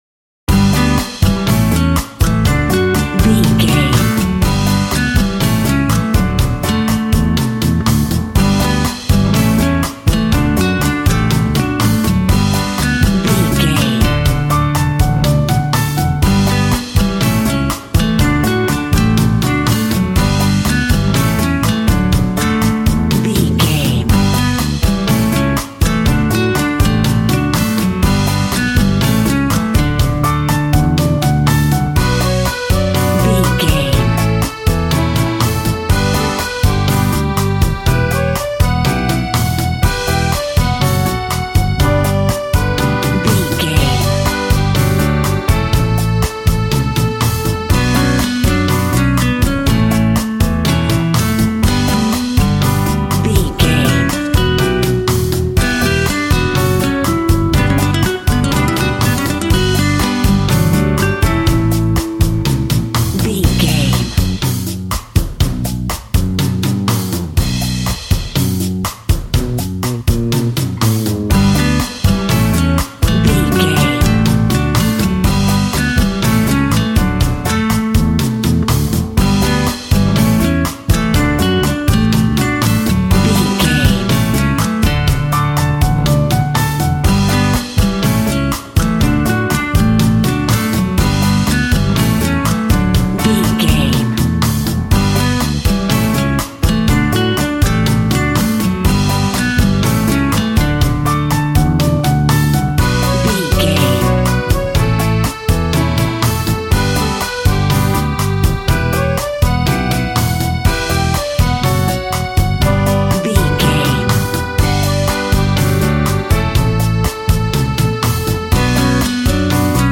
Aeolian/Minor
funky
energetic
romantic
percussion
electric guitar
acoustic guitar